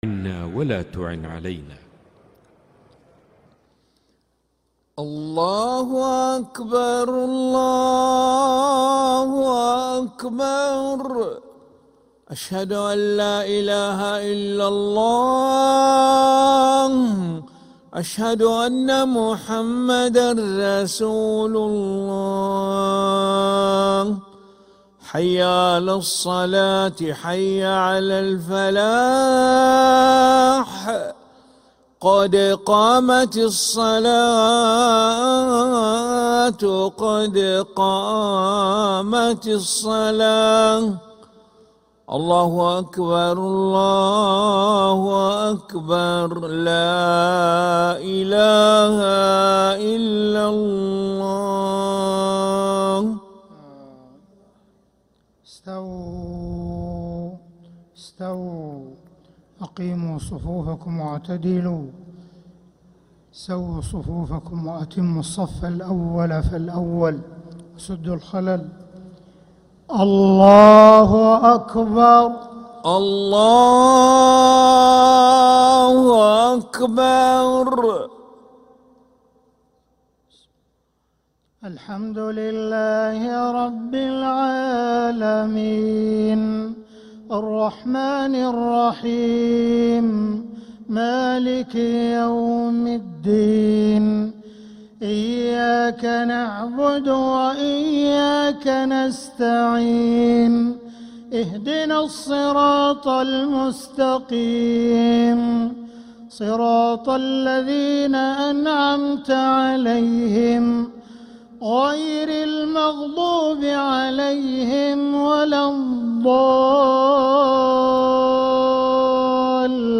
Haramain Salaah Recordings: Makkah Isha - 19th April 2026
Makkah Isha (Surah Al-Buruj) Sheikh Sudais Download 128kbps Audio